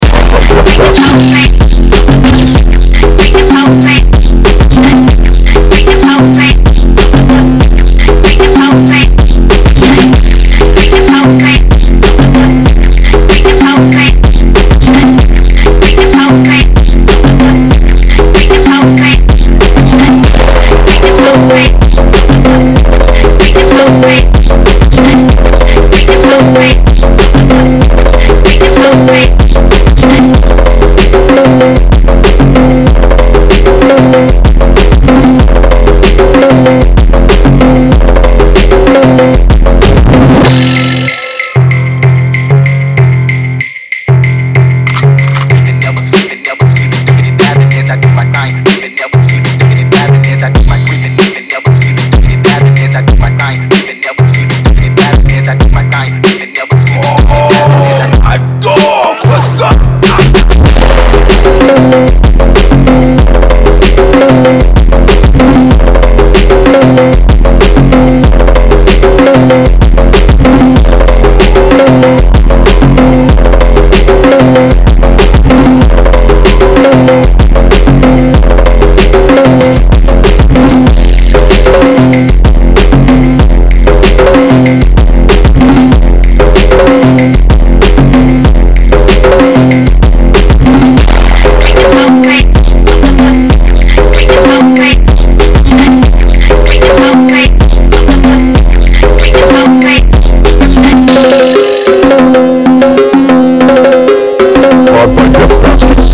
Фонк